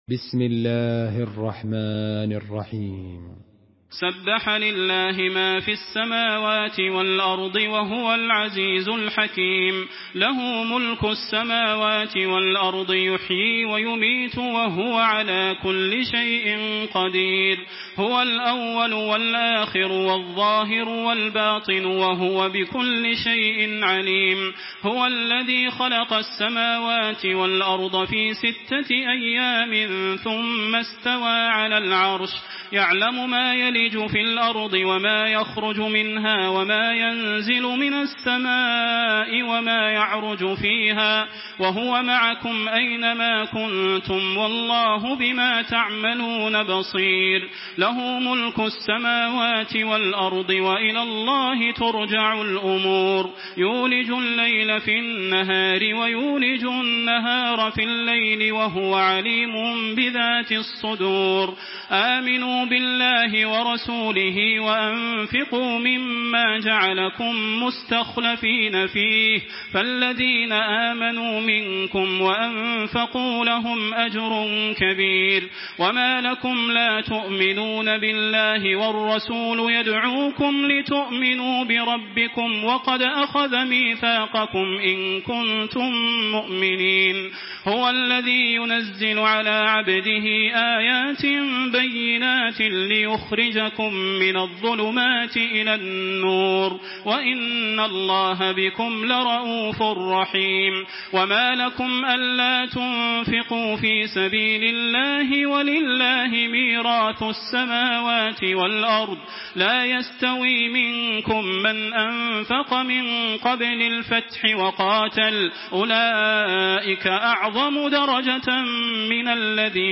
تراويح الحرم المكي 1426
مرتل